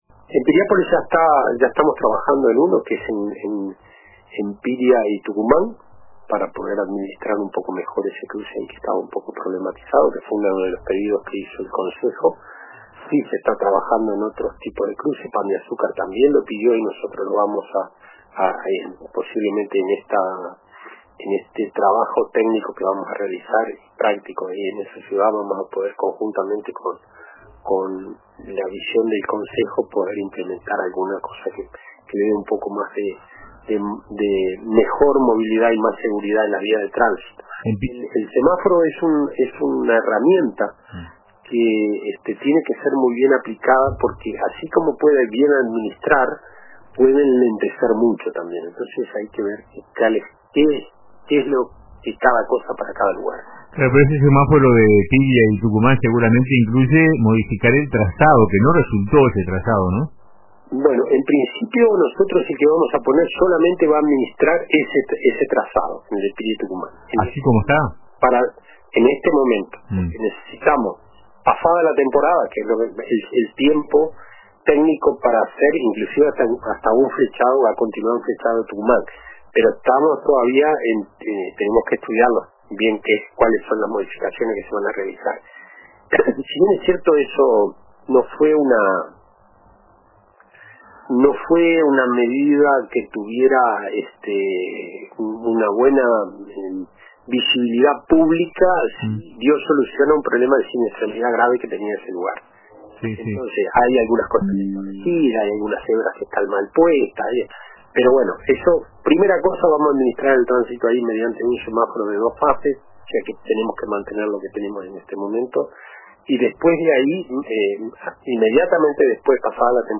Juan Pígola, director general del Departamento de Movilidad de la Intendencia de Maldonado, fue entrevistado en el programa Radio con Todos de RBC, donde se refirió a la instalación de un semáforo en la intersección de las calles Piria y Tucumán.